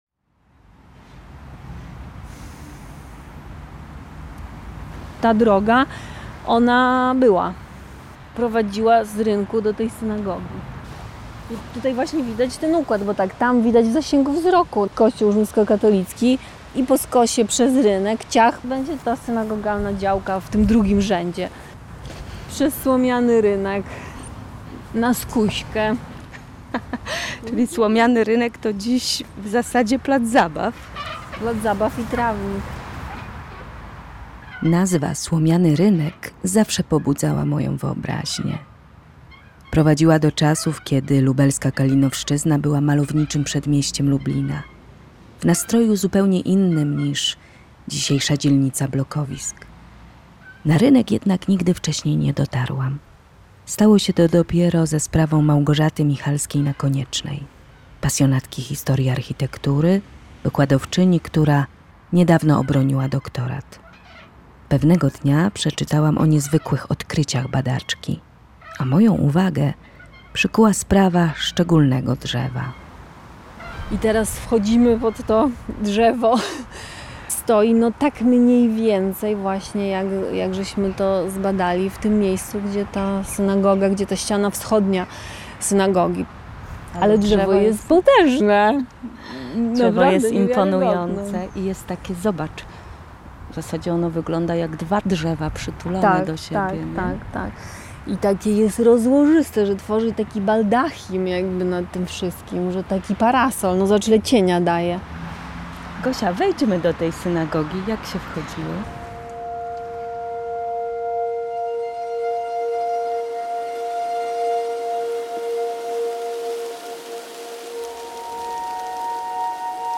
Pełna pasji opowieść badaczki kieruje naszą uwagę na te obszary, gdzie nauka spotyka się z metafizyką.